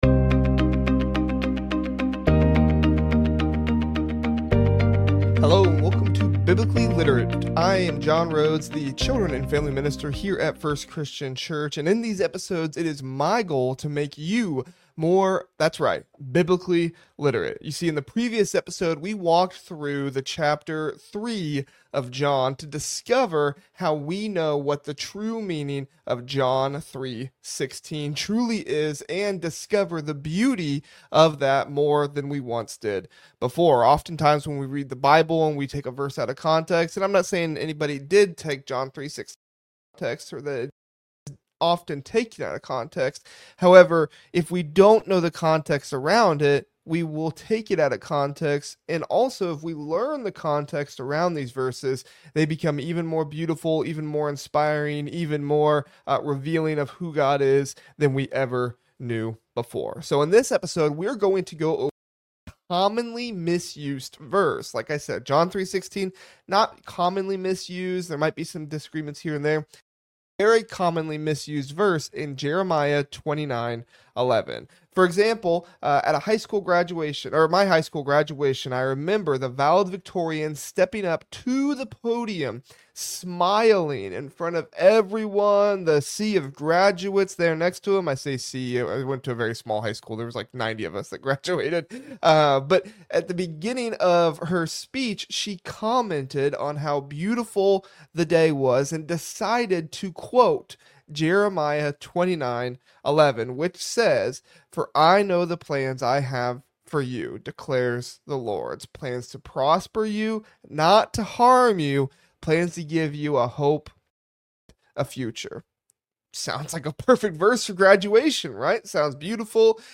First Christian Church of Brazil Indiana Sermons